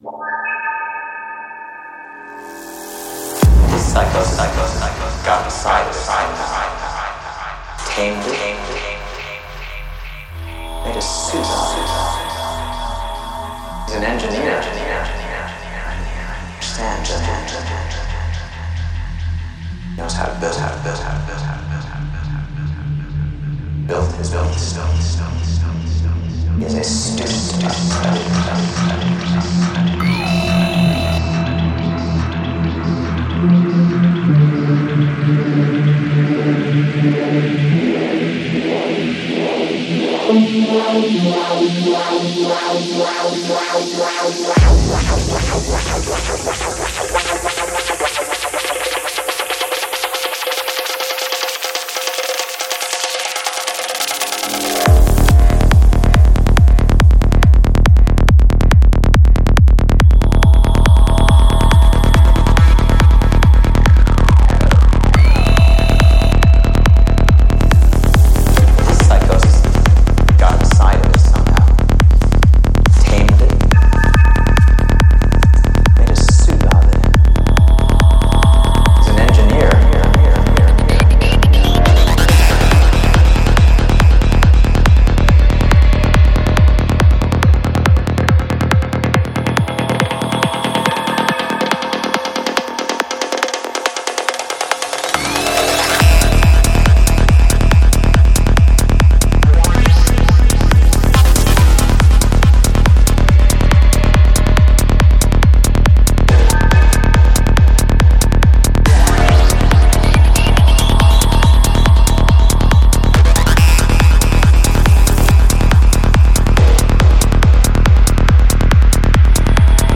Жанр: Транс